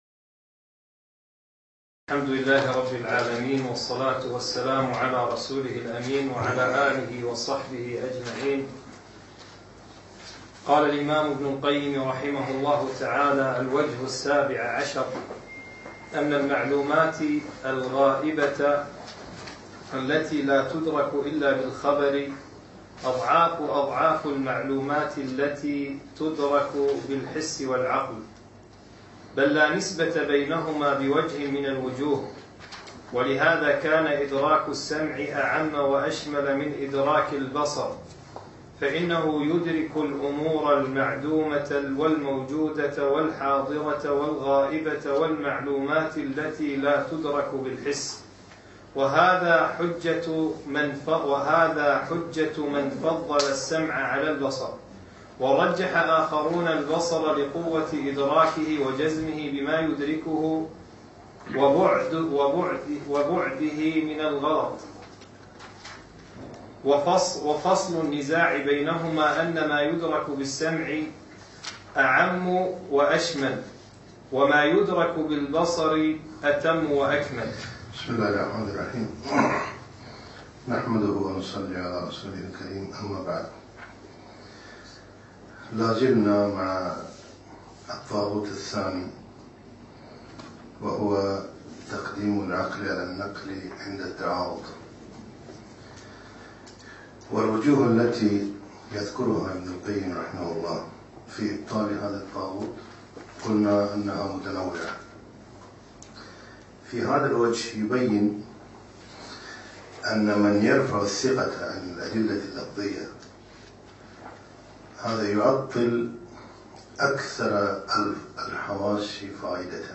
أقيم الدرس في ديوان مشروع الدين الخالص يوم الخميس 2 4 2015